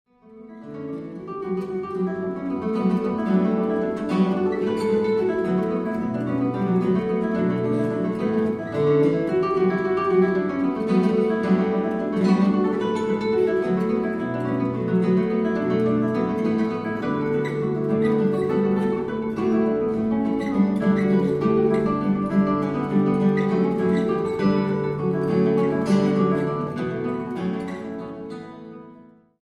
for two guitars